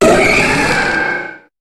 Cri de Méga-Scarhino dans Pokémon HOME.
Cri_0214_Méga_HOME.ogg